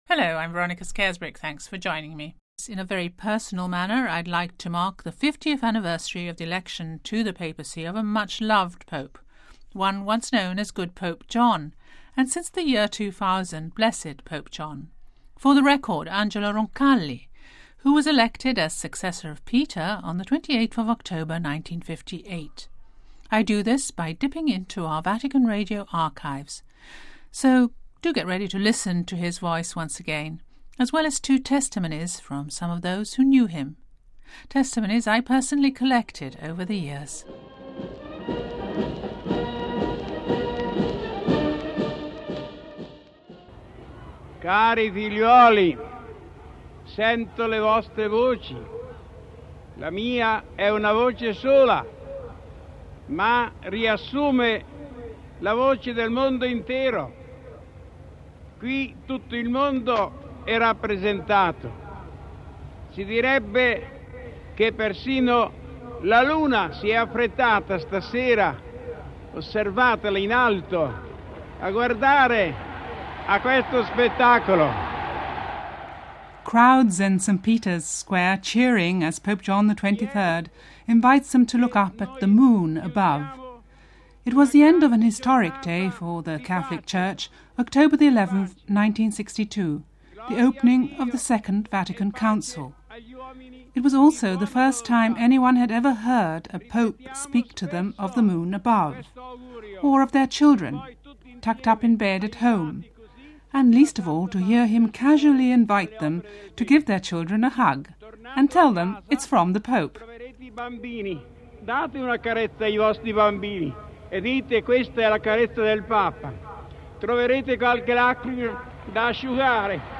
The pope in question is Blessed John XXIII known as Good Pope John. Listen to Part 2 in this series to mark 50 years since his election as Successor of Peter, which includes exclusive sound from our Vatican Radio archives, as well as some very personal testimonies from some of those who knew him..